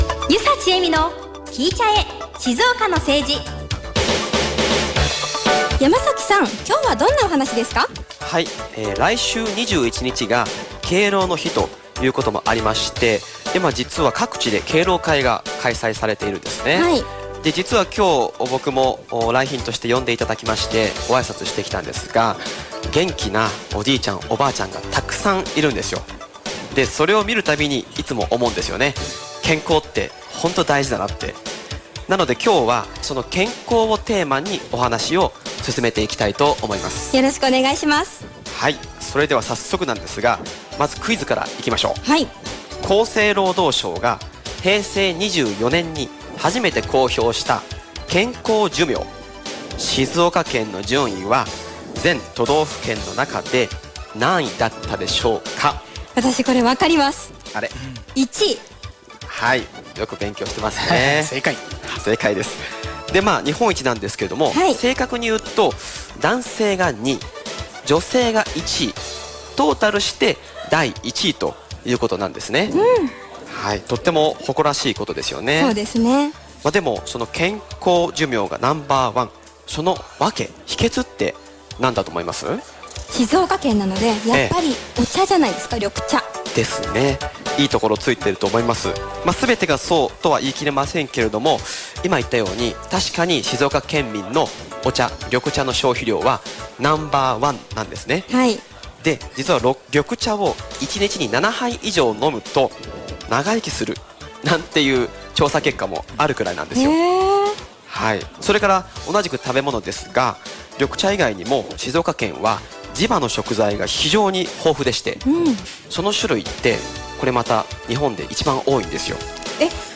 所属している会派「ふじのくに県議団」の西部選出若手議員が、 毎週土曜日１７：００からの３０分間、週替わりで登場します！